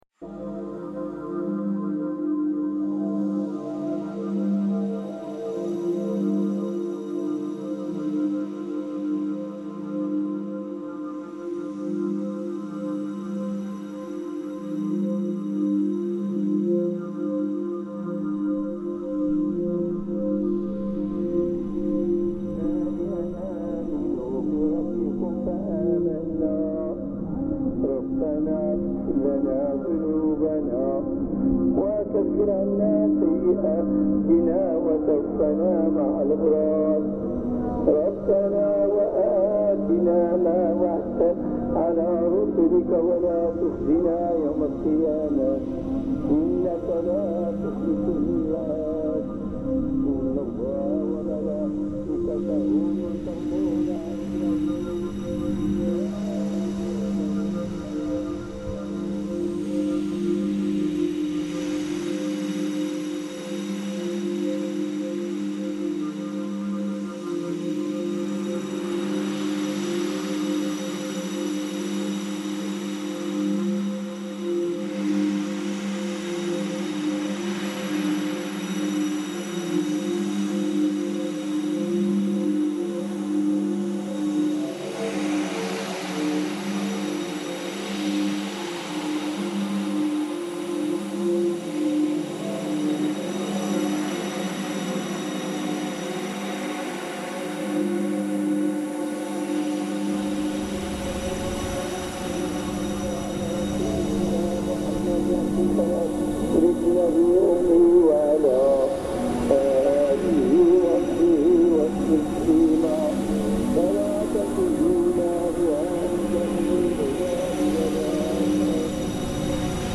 Fajr call to prayer reimagined